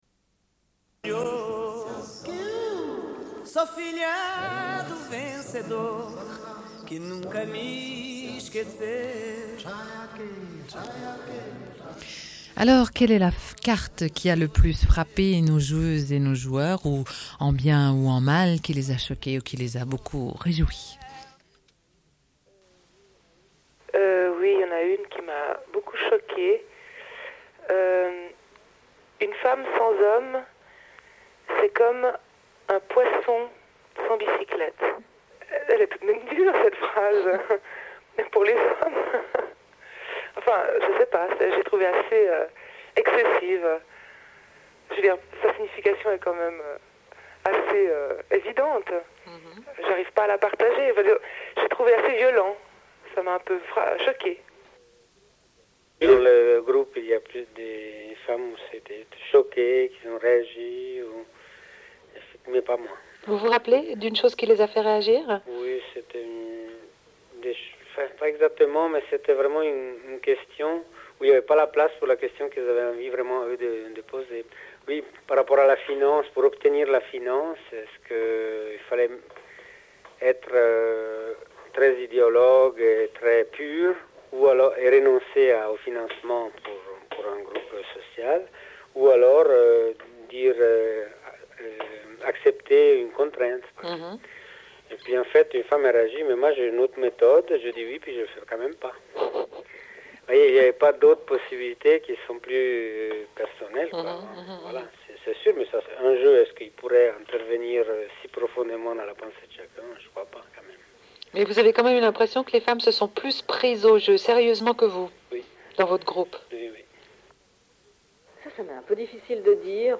Une cassette audio, face B31:11
Radio